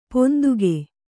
♪ ponduge